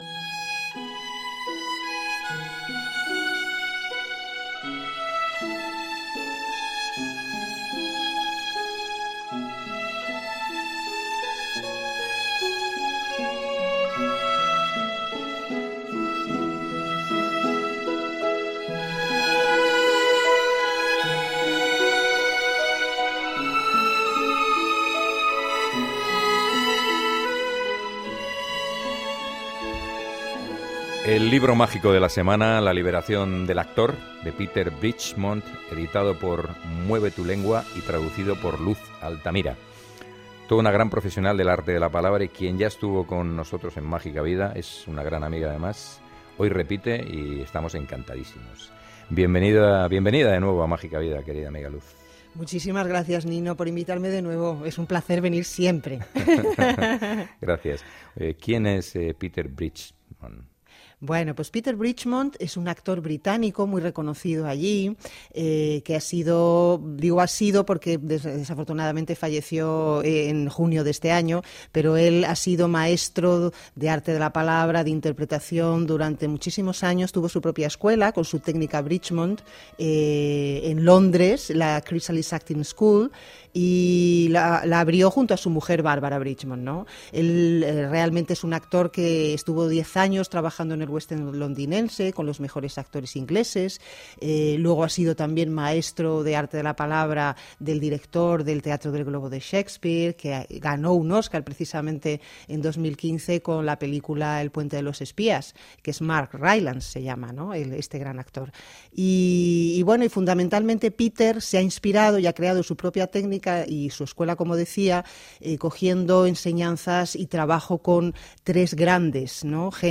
Entrevista
Programa de Radio